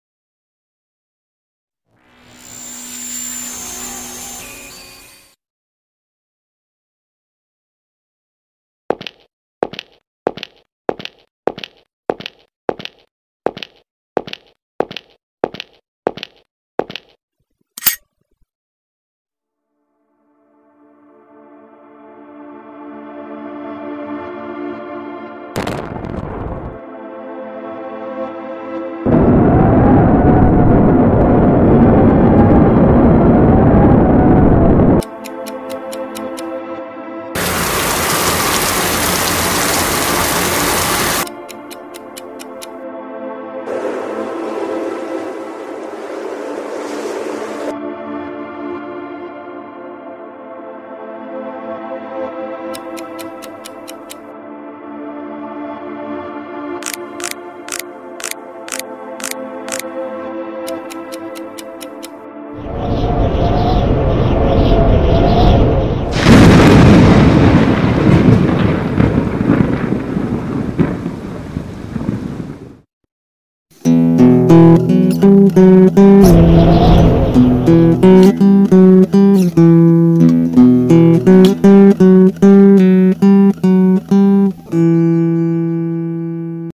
The Click Animated Horror sound effects free download
Scary Animation - Disturbing Video Sounds